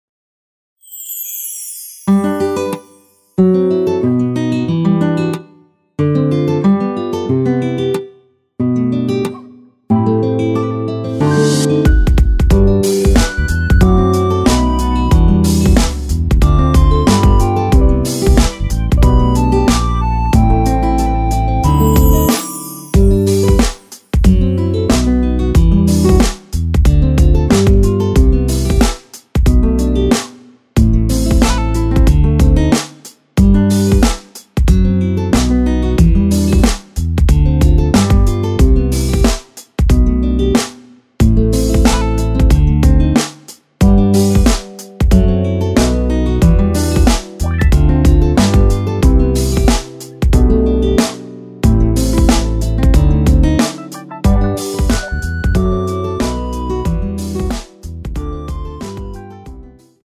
엔딩이 페이드 아웃이라 라이브 하시기 좋게 엔딩을 만들어 놓았습니다.
앞부분30초, 뒷부분30초씩 편집해서 올려 드리고 있습니다.